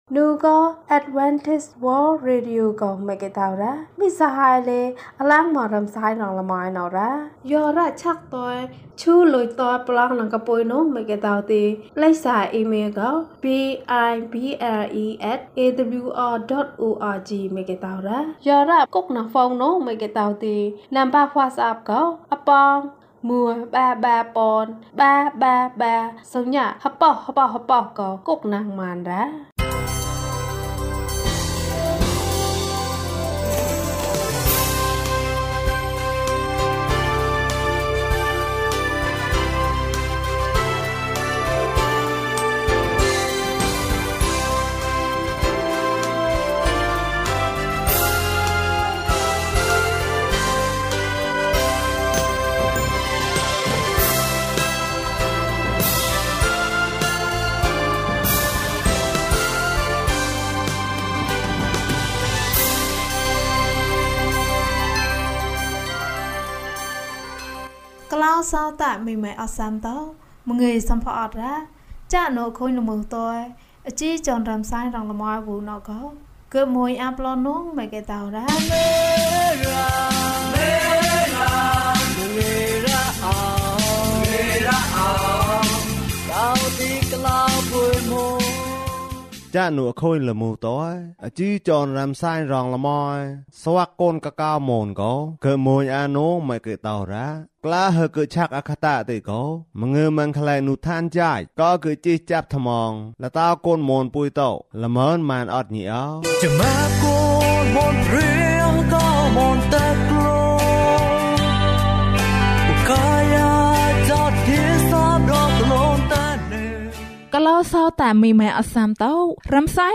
ခရစ်တော်ထံသို့ ခြေလှမ်း။၂၈ ကျန်းမာခြင်းအကြောင်းအရာ။ ဓမ္မသီချင်း။ တရားဒေသနာ။